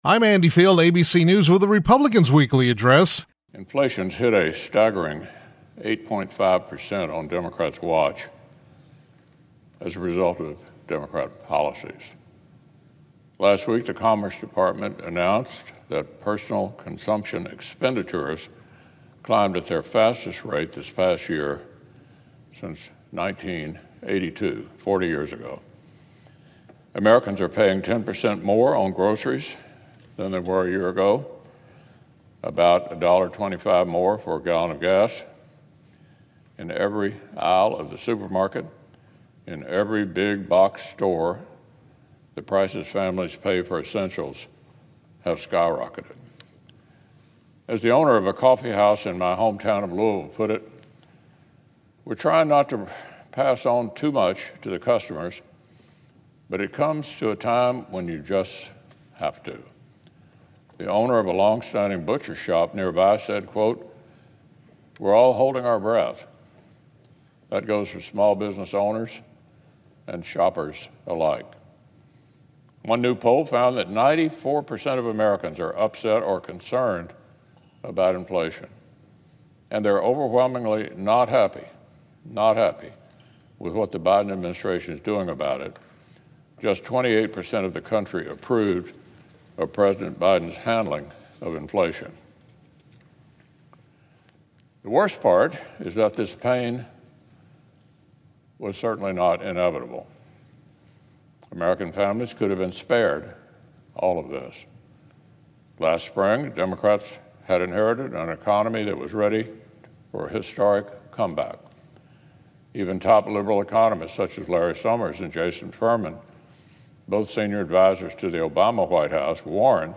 U.S. Senate Republican Leader Mitch McConnell (R-KY) delivered remarks on the Senate floor regarding inflation and reckless spending.